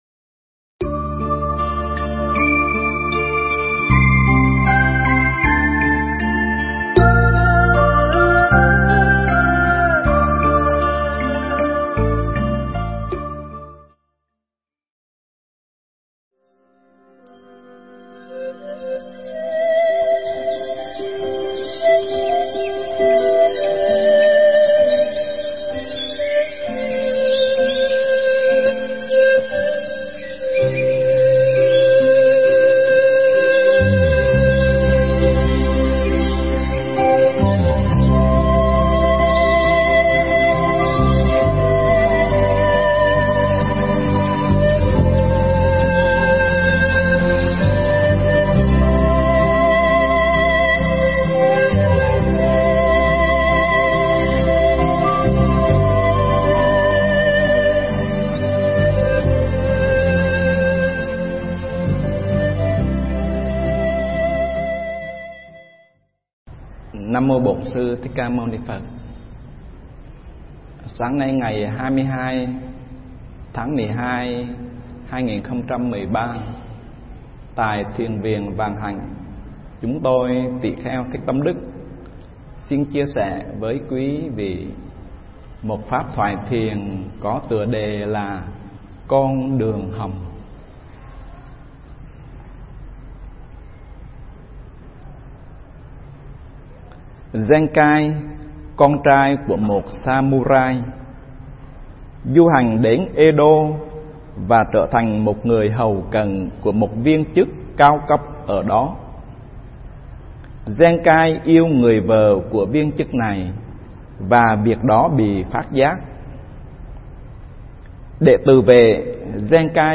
Nghe Mp3 thuyết pháp Con Đường Hầm